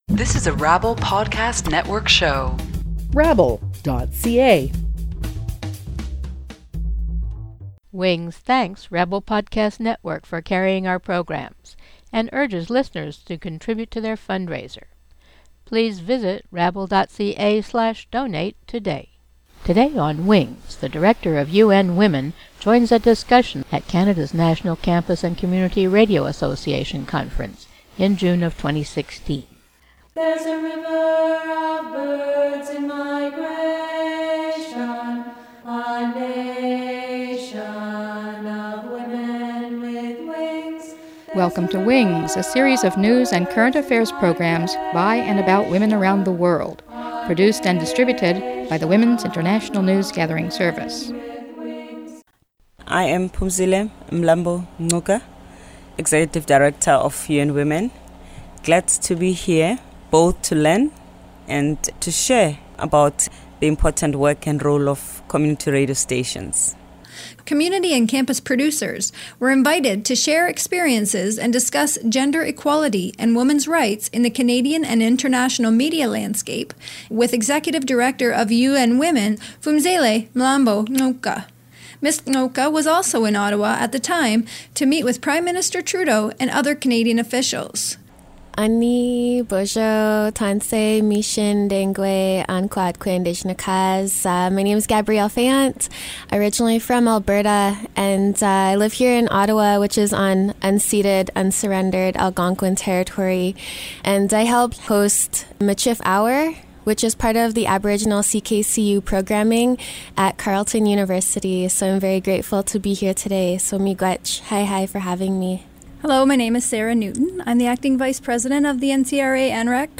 Description: During an organic discussion with four members of Canada's National Campus and Community Radio Association, held at CHUO Radio Studios at the University of Ottawa ahead of the Association's annual conference, the Executive Director fielded questions on violence against Indigenous women, how to include transgender people in the conversation on women's rights, and how Canada can take a global lead on ending discrimination. She urged broadcasters to use their influence to change the world and make a difference.